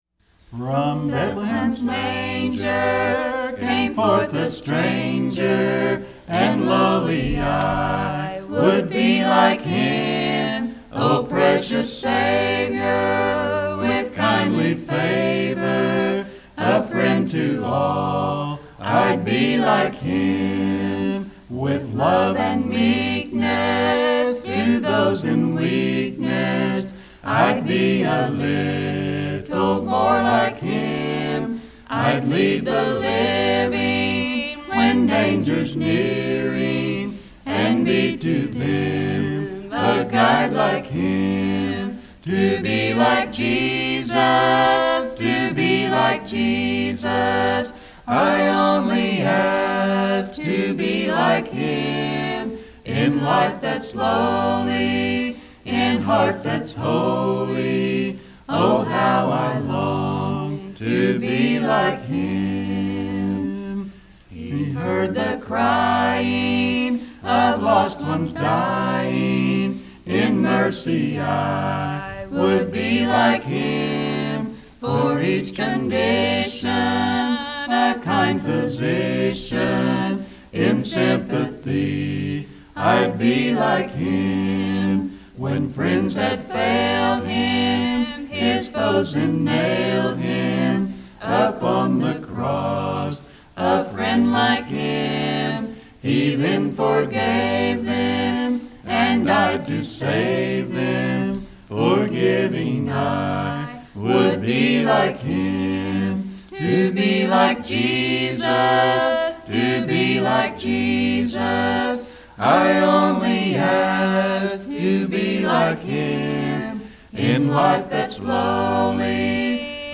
Key: A♭